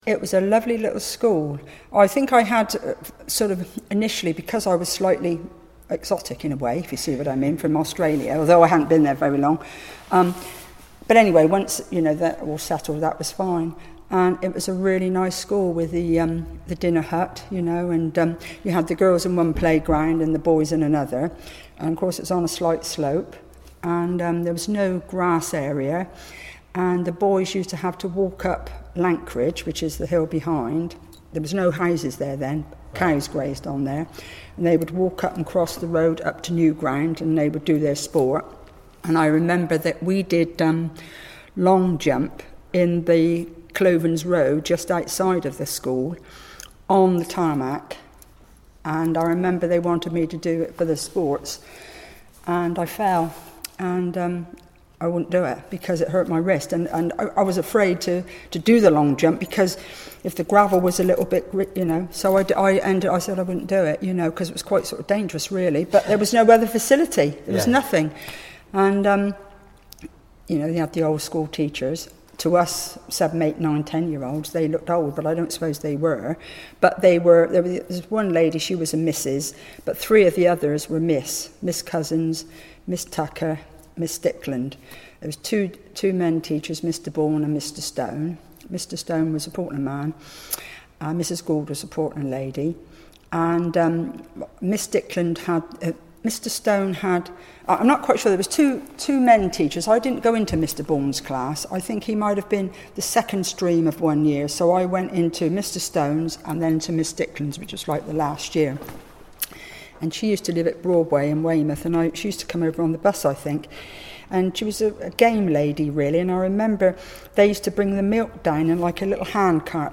ABSOLUTE THEATRE in partnership with learners from the ISLE OF PORTLAND ALDRIDGE COMMUNITY ACADEMY, recorded these anecdotes, memories and experiences of Portland people.